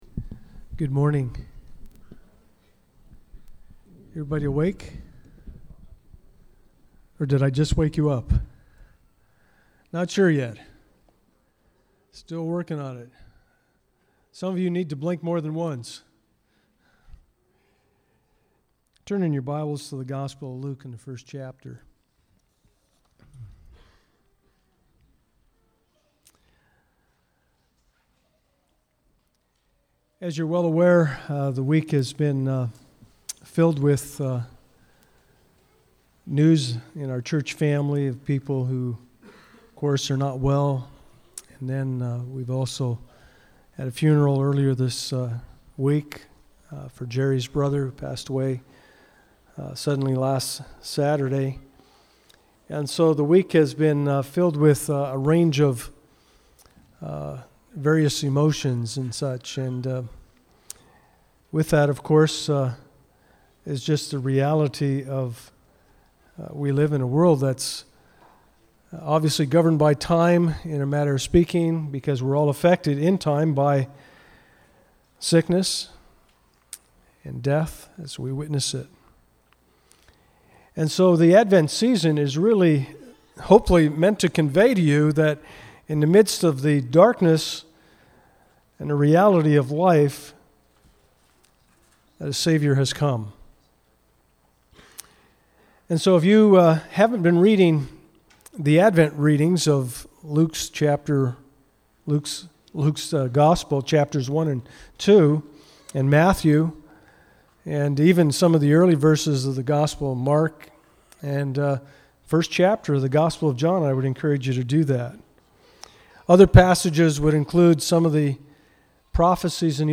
Passage: Luke 1:1-10 Service Type: Sunday Morning « Communion Preparing God’s People for the Lord’s Coming